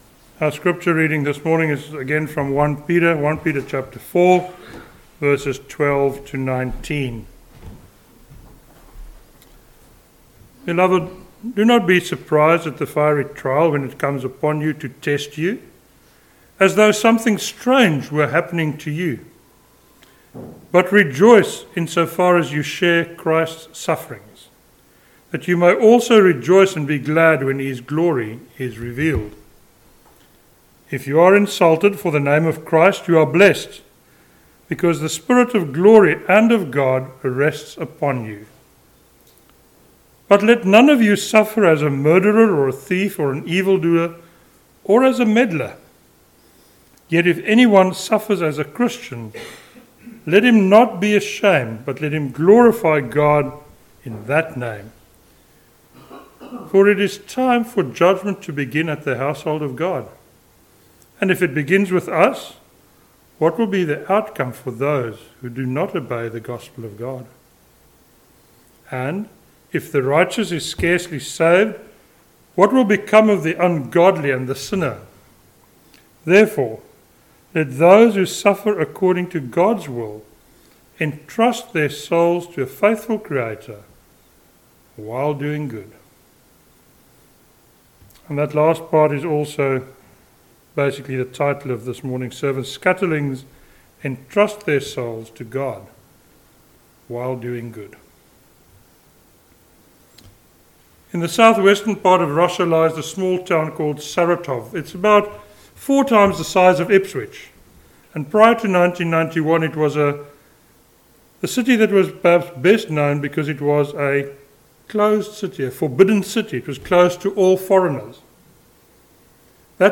a sermon on 1 Peter 4:12-19